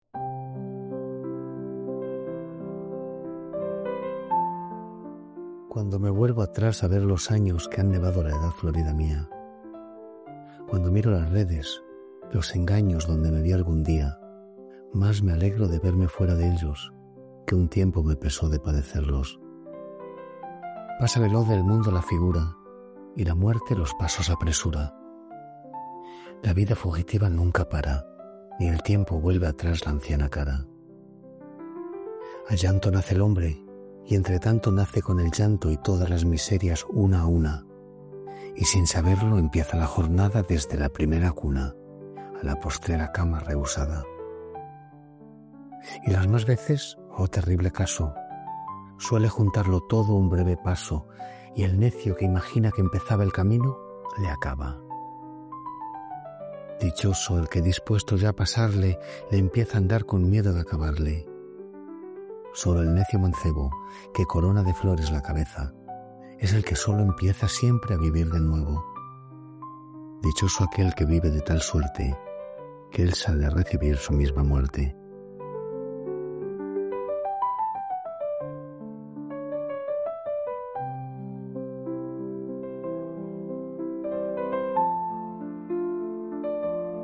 Quevedo.-Cuando-me-vuelvo-musica-enhanced-v2.mp3